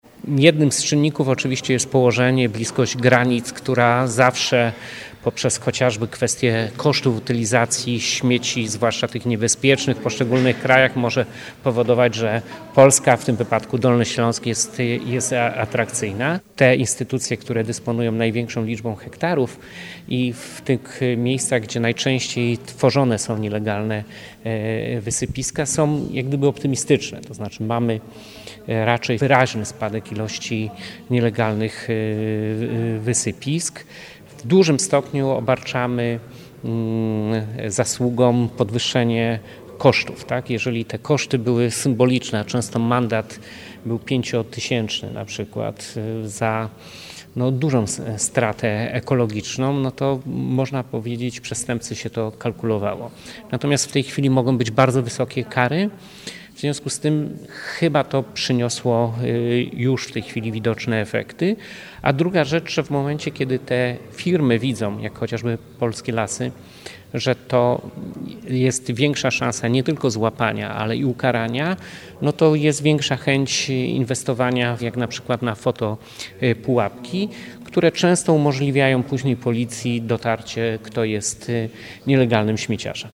– Na Dolnym Śląsku jest dużo nielegalnych składowisk odpadów – mówi Jarosław Obremski, wojewoda Dolnośląski.